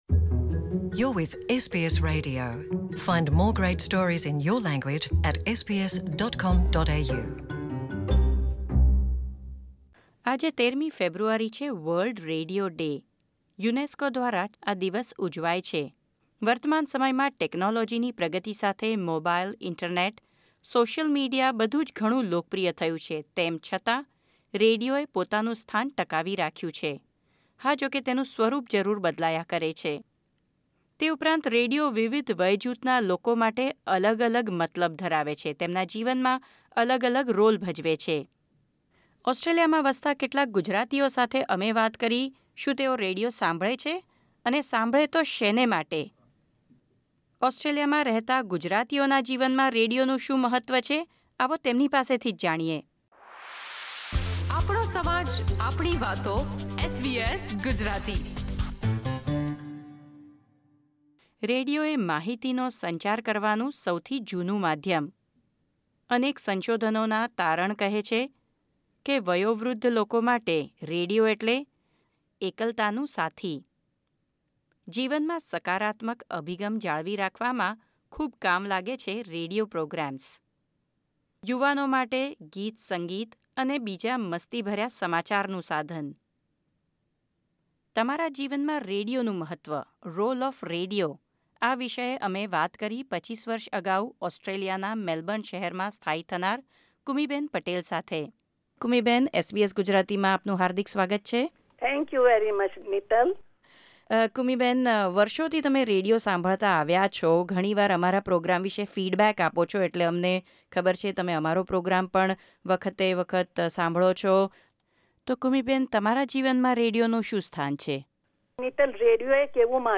SBS Gujarati Radio listeners talk about role of radio in their lives Source: SBS Gujarati